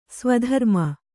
♪ svadharma